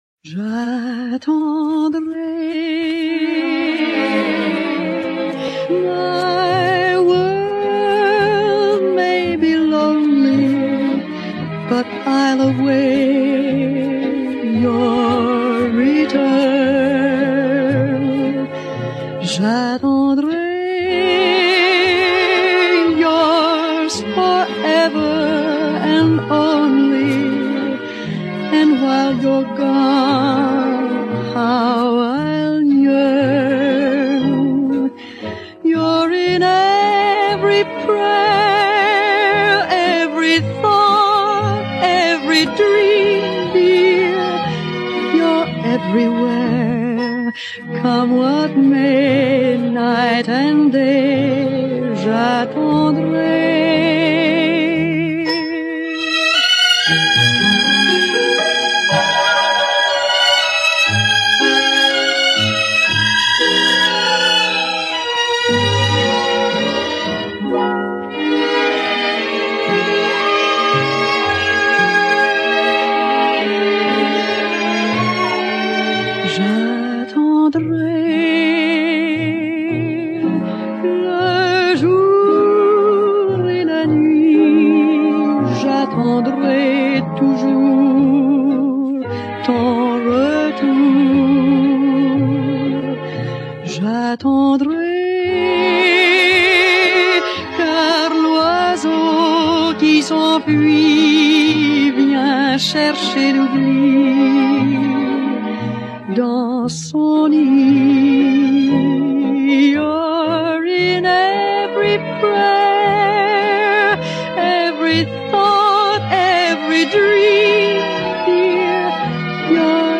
Dalla bella voce